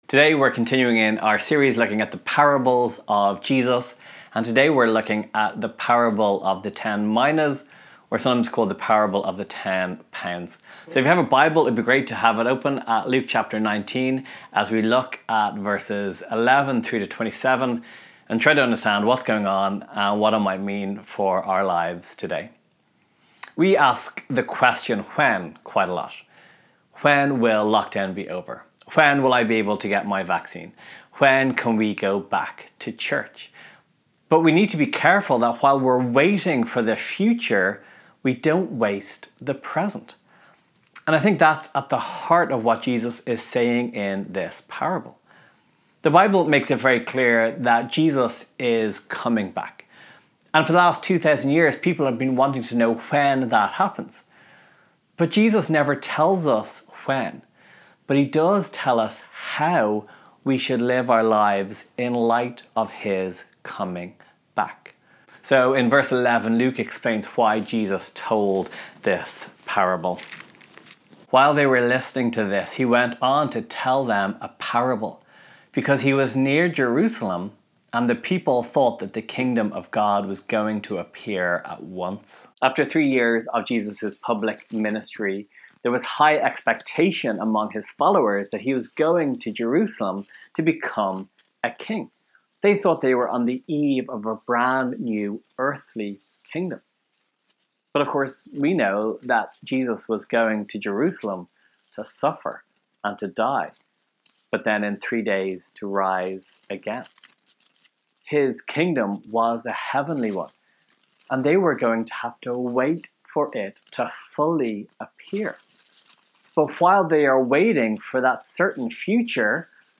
Downloads March/Easter Reading Plan Download Sermon MP3 Share this: Share on X (Opens in new window) X Share on Facebook (Opens in new window) Facebook Like Loading...